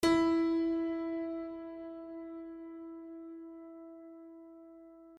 piano-sounds-dev
HardPiano
e3.mp3